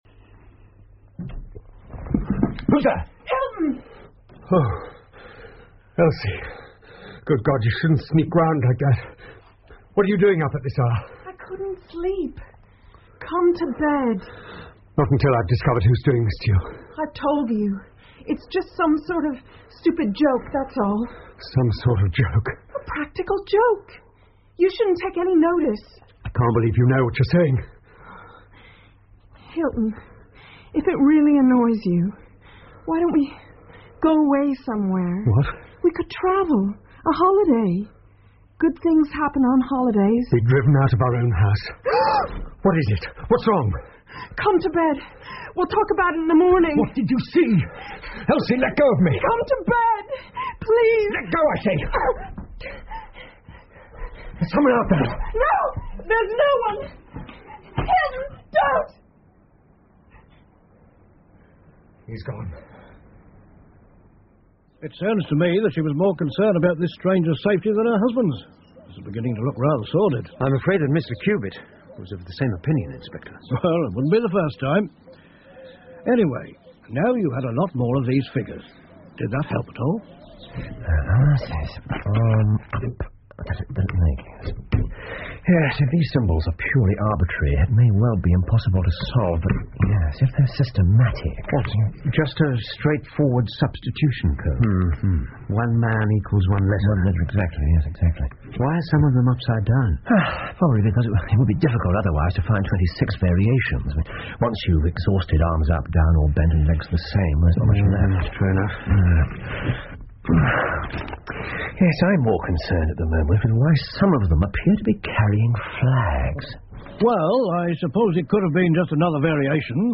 福尔摩斯广播剧 The Dancing Men 6 听力文件下载—在线英语听力室